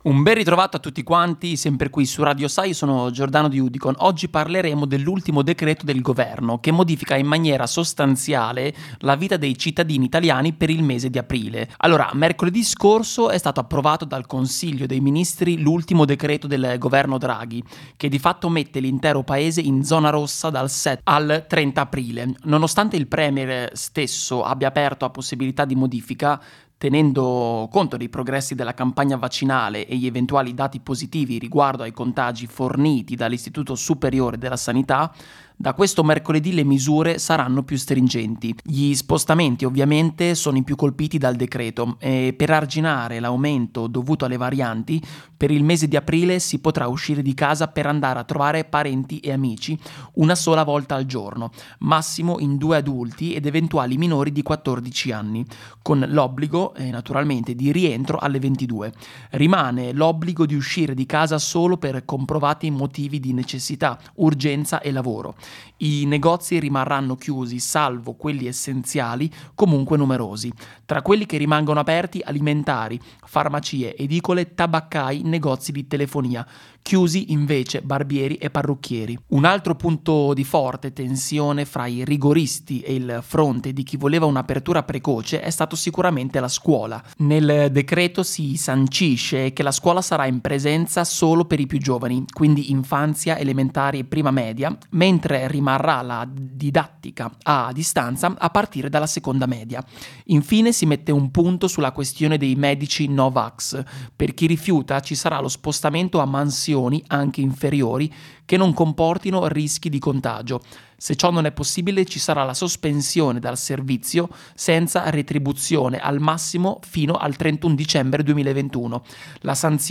Iniziativa telematica del 06/04/2021 su Radio Sa